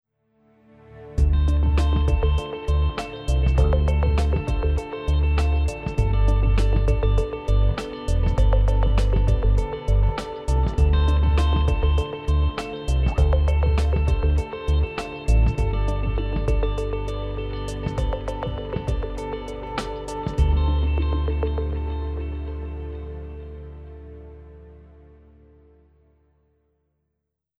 [Scoremusik]